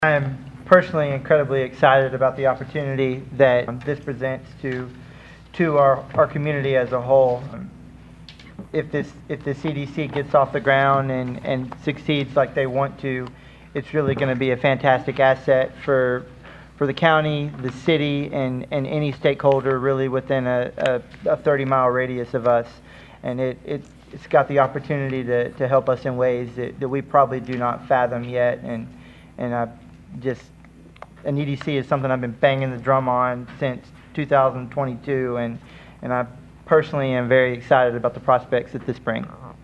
Ward 4 Councilmember Jon Strong commented on the agreement.